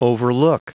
Prononciation du mot overlook en anglais (fichier audio)
Prononciation du mot : overlook